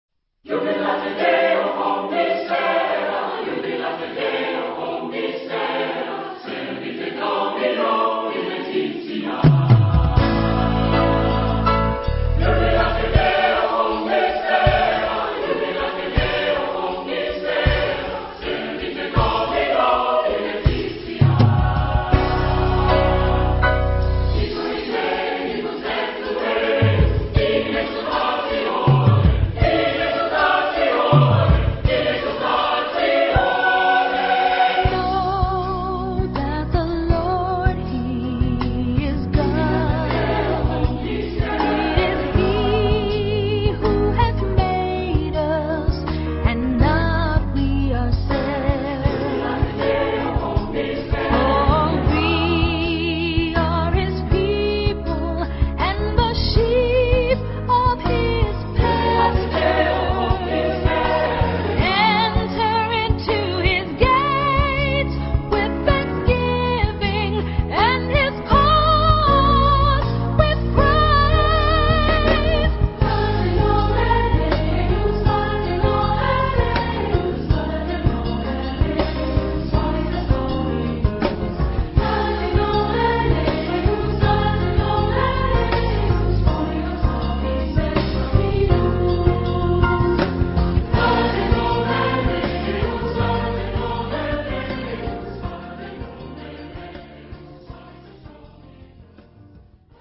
Genre-Style-Forme : Gospel ; Jazz ; Sacré
Caractère de la pièce : joyeux ; con brio
Type de choeur : SSAATB  (6 voix mixtes )
Solistes : Soprano (1) OU Ténor (1)  (1 soliste(s))
Instruments : Piano (1) ; Guitare basse (1) ; Batterie (1)